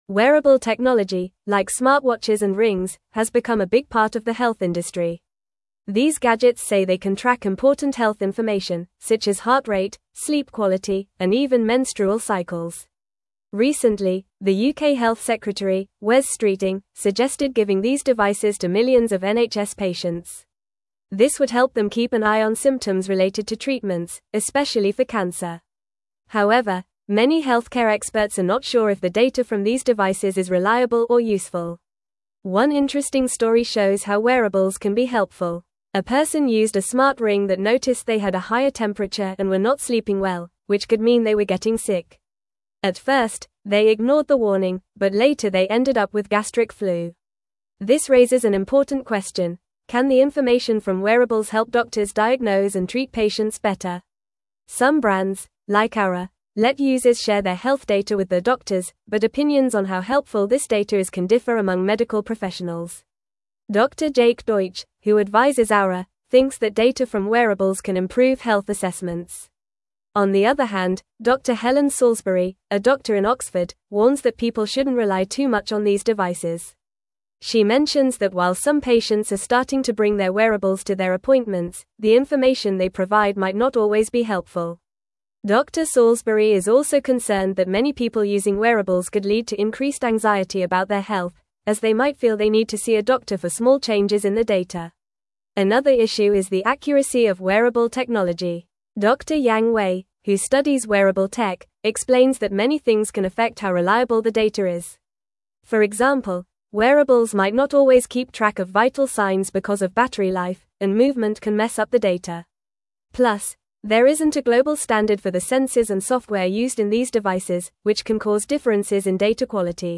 Fast
English-Newsroom-Upper-Intermediate-FAST-Reading-Wearable-Technologys-Impact-on-Healthcare-Benefits-and-Concerns.mp3